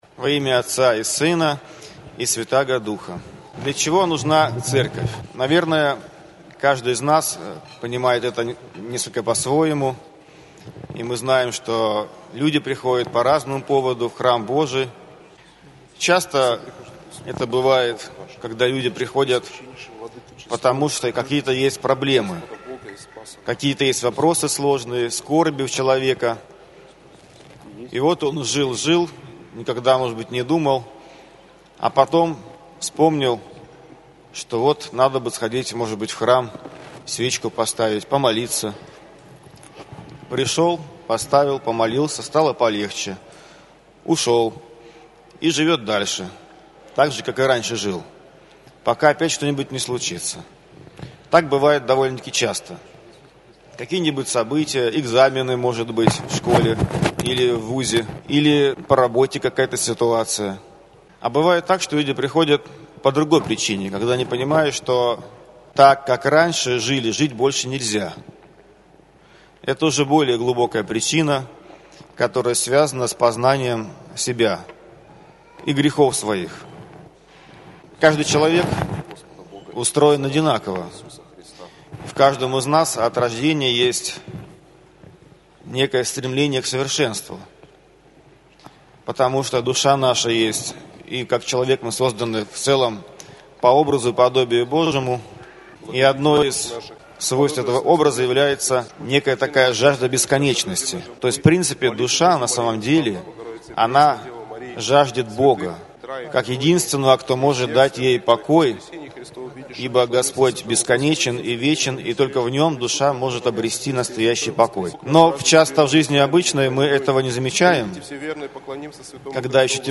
Проповедь в Неделю 13-ю по Пятидесятнице - Кафедральный собор Христа Спасителя г. Калининграда